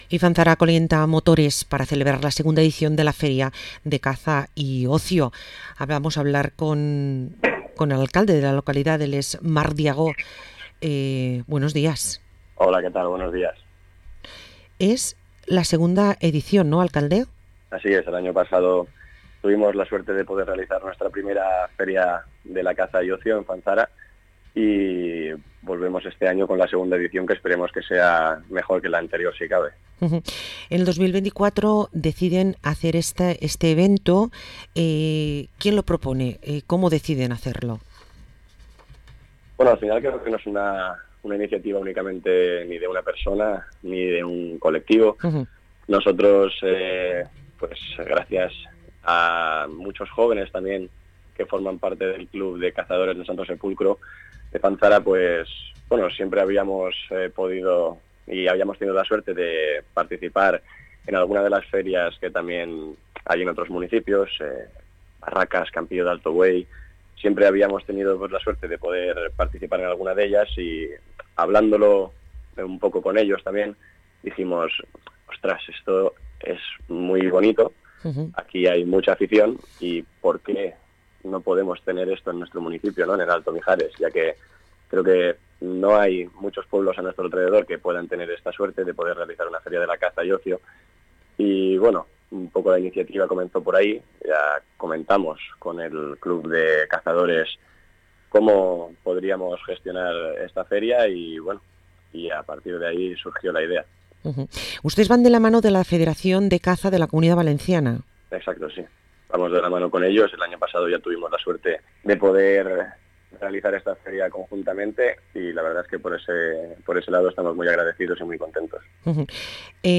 Parlem amb l´alcalde de Fanzara, Marc Diago